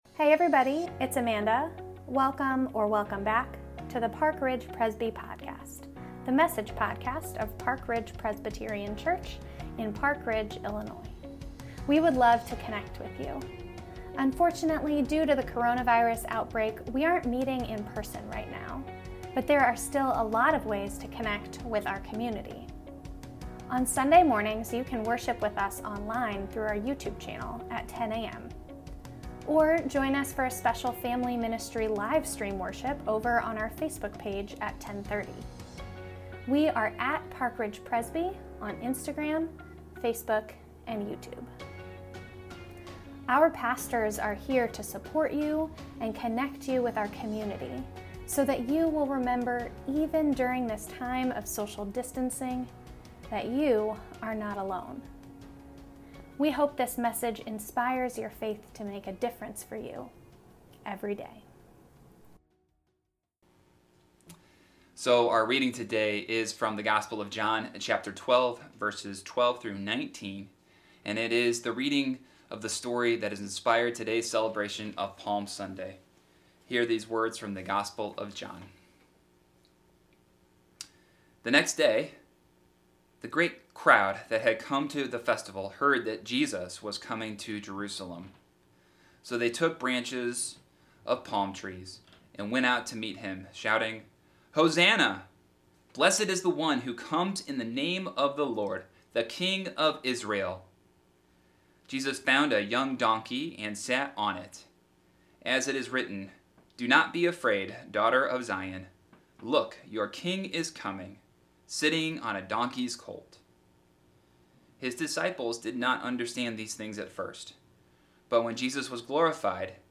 Sunday, April 5, 2020 – Wisdom of the Crowd – Palm Sunday – Park Ridge Presbyterian Church
A message